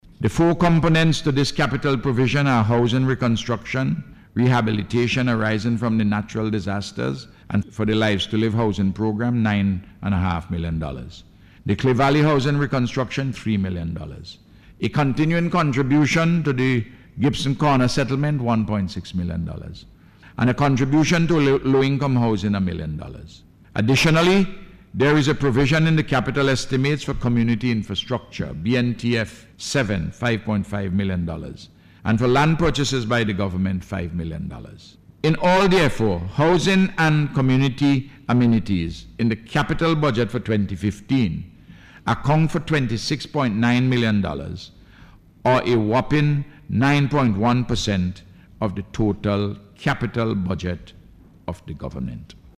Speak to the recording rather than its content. In his Budget Address on Wednesday, the Prime Minister said he Government will continue its Rehabilitation and Reconstruction Program.